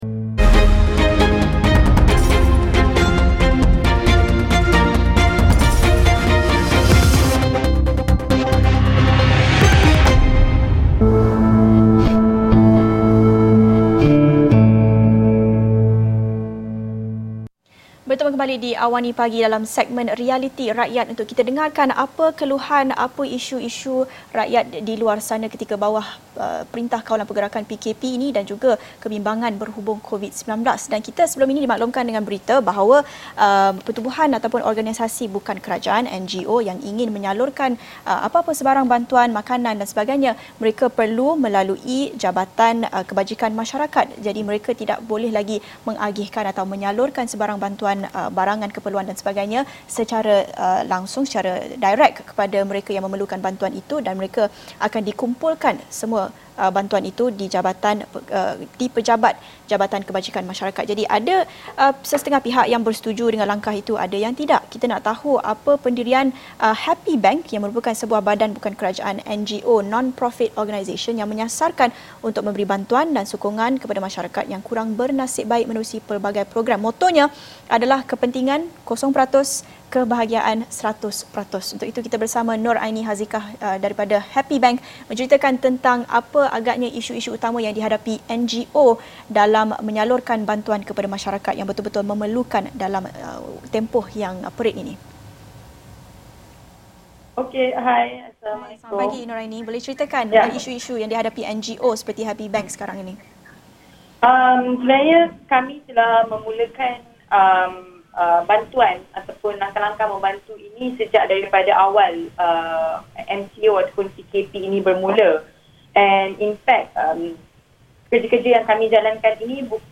Episod ini telah disiarkan secara langsung dalam program AWANI Pagi, di saluran 501, jam 8:30 pagi.